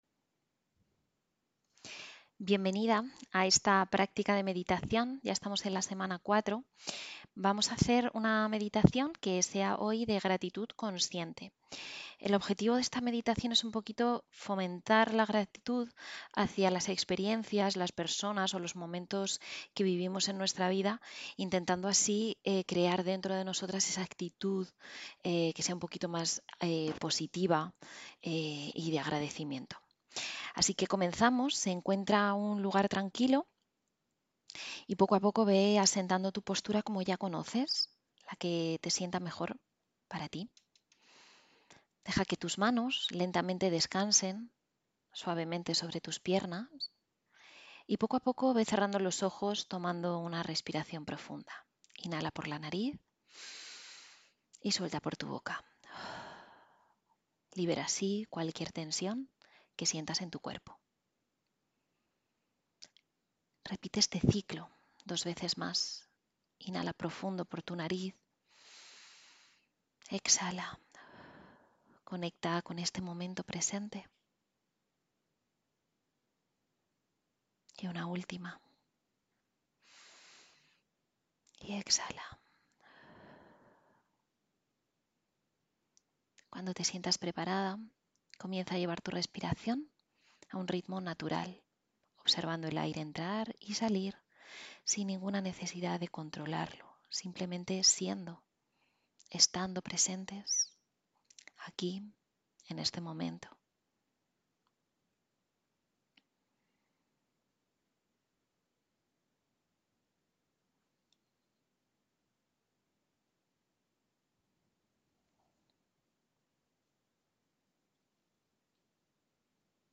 Sesión 10: Meditación de Gratitud